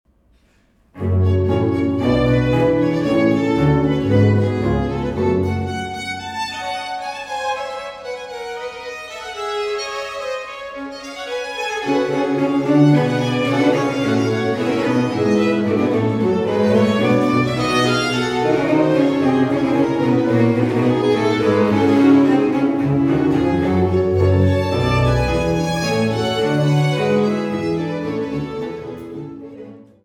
Instrumetalmusik für Hof, Kirche, Oper und Kammer